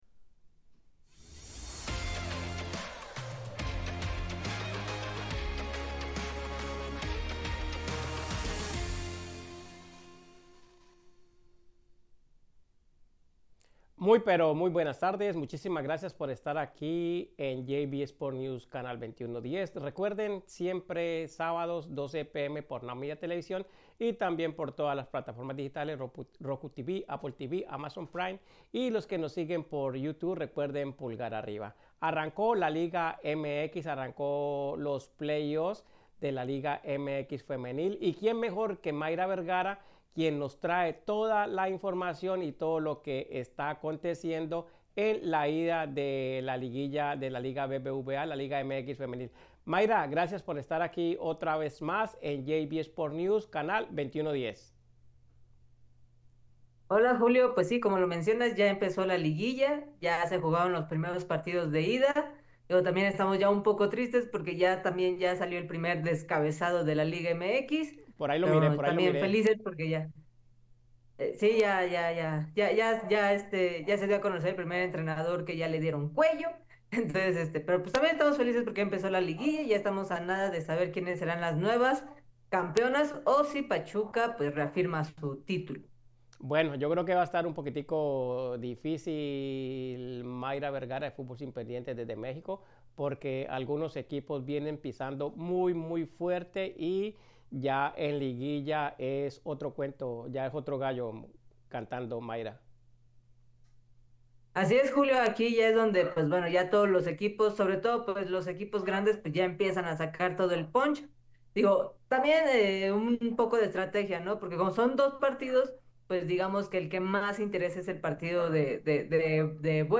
JV Sport News 11-08-25 | Liga MX Femenil, Mundial Sub-17 y entrevista exclusiva